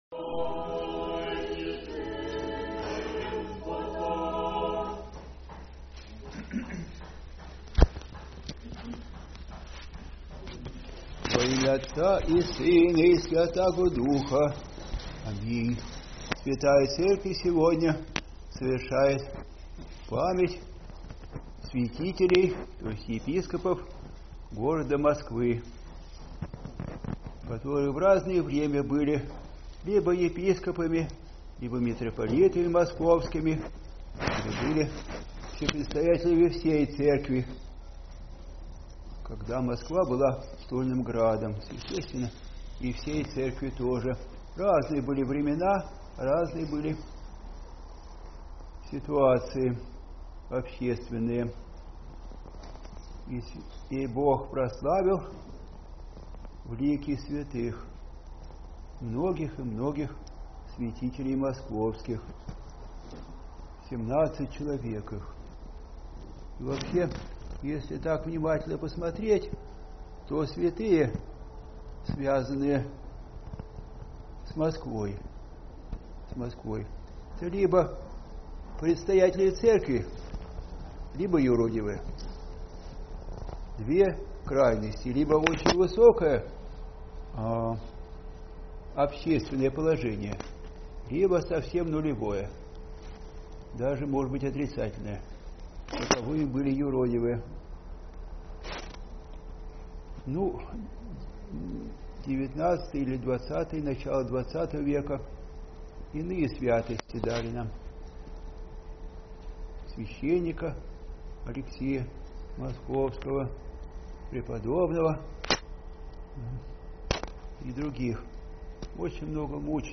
Аудиопроповеди